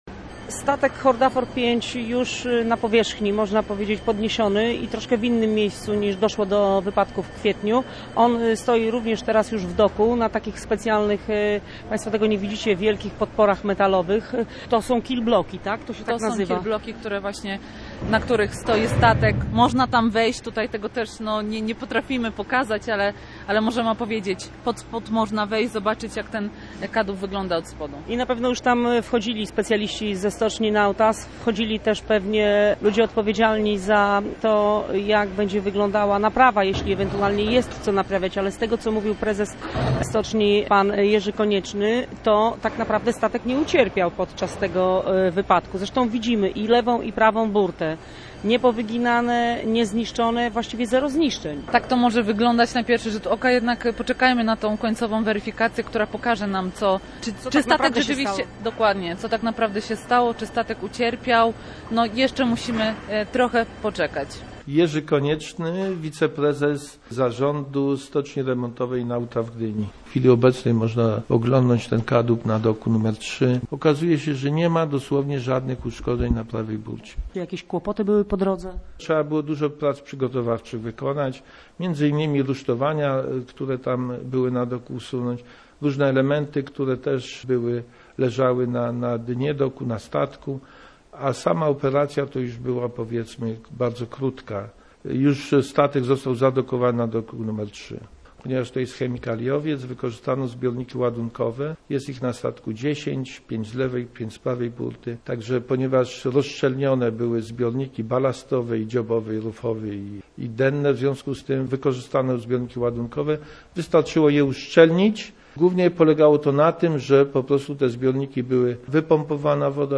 rozmowa_z_przedstawicielami_stoczni_nauta_o_podnoszonym_statku35.mp3